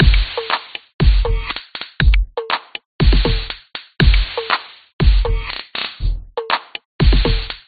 TbeatLoop120BPM
Tag: 节拍 循环 鼓声